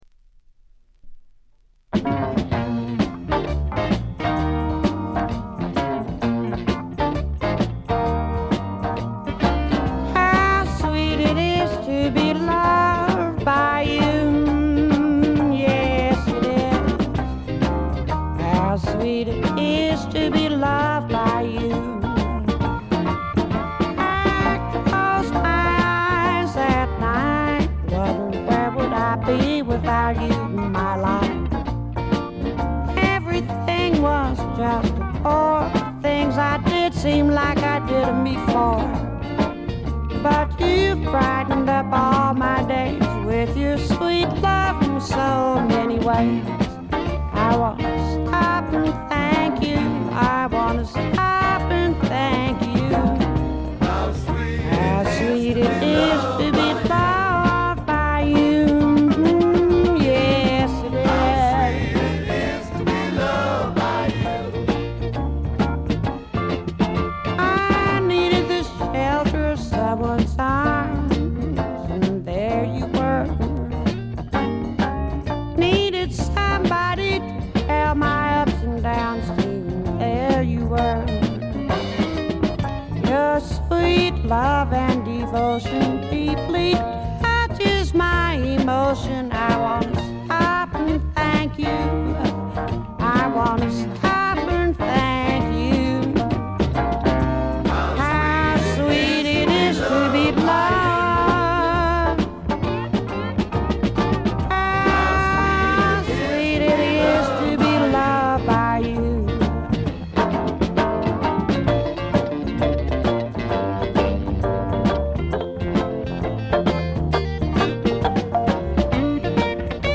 静音部での微細なチリプチが少しだけ。
試聴曲は現品からの取り込み音源です。
Vocals, Banjo, 12 String Guitar